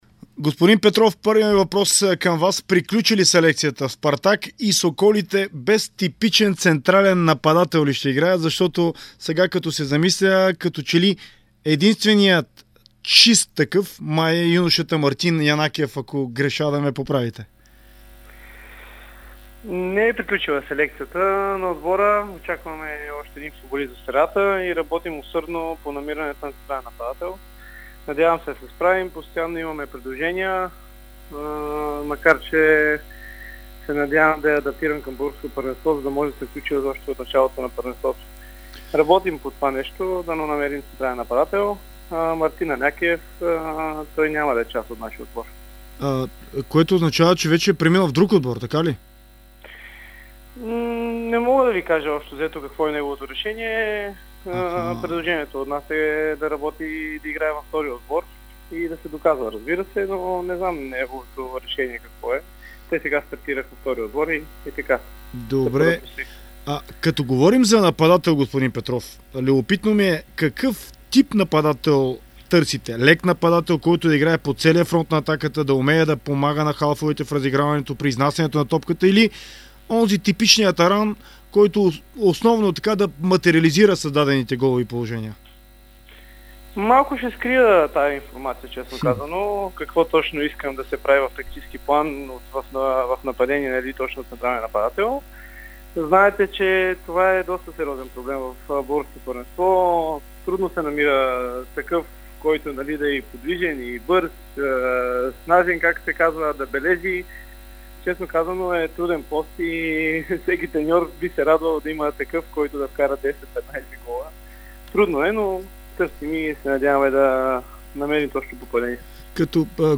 говори в интервю за Дарик радио и dsport за селекцията в Спартак, за усиленото търсене на централен нападател, за конкуренцията в отбора и адаптирането на чужденците.